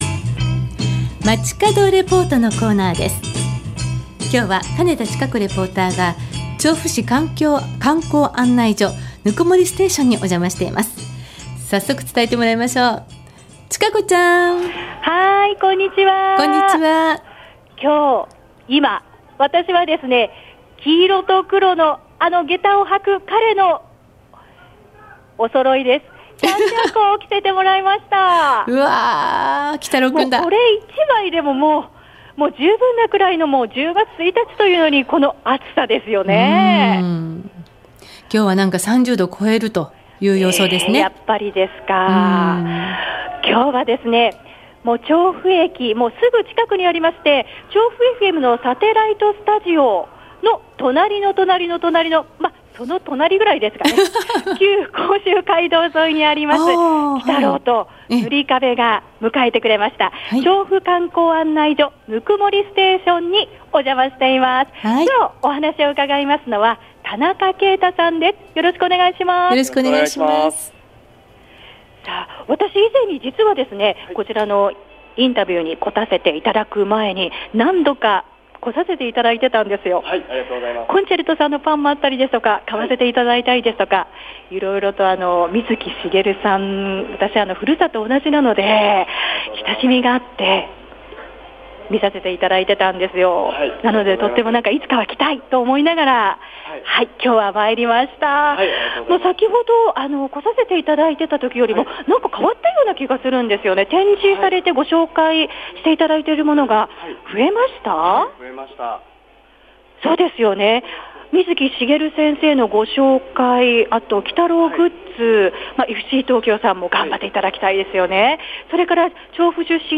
午後のカフェテラス 街角レポート
「ホント楽しい～」と2人の楽しい声を聞かせてもらえました。